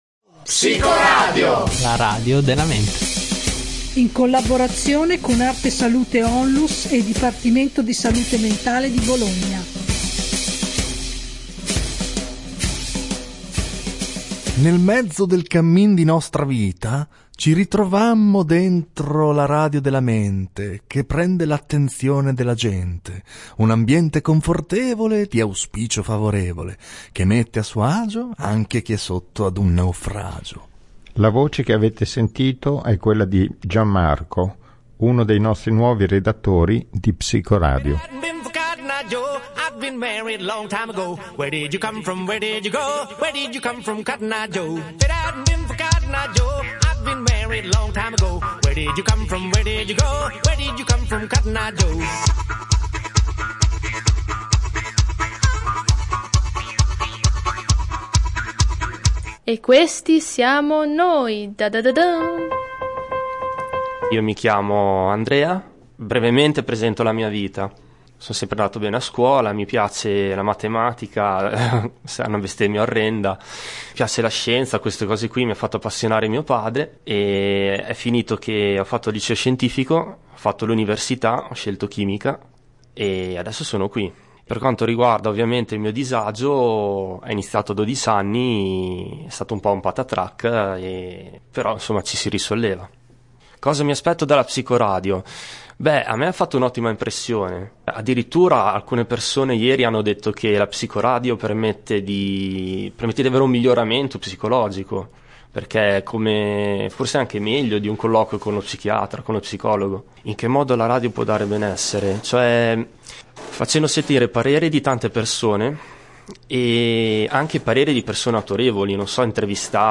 Clicca qui per ascoltare la puntata I nuovi redattori di Psicoradio si presentano Sono in tanti alla redazione di Psicoradio, dalla fine di gennaio sono arrivati sette redattori nuovi di zecca: tre ragazze e quattro ragazzi.